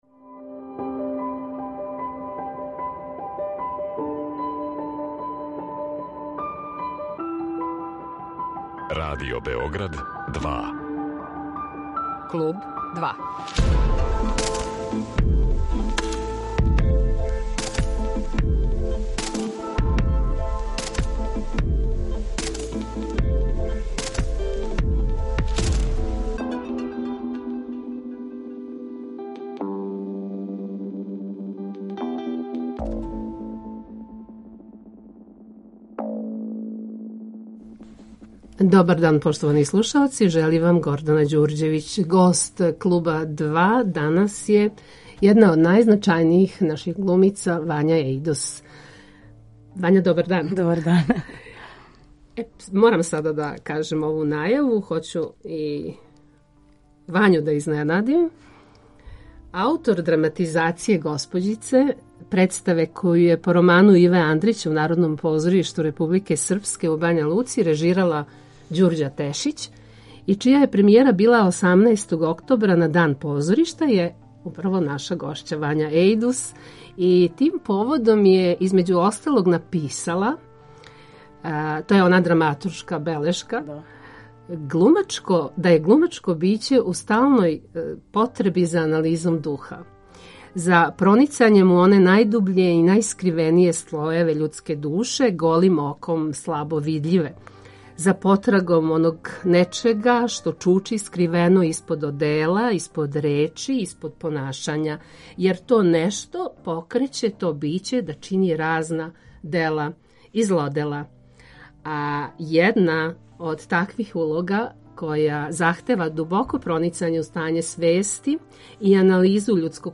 Гост Клуба 2 је једна од наших најзначајнијих глумица Вања Ејдус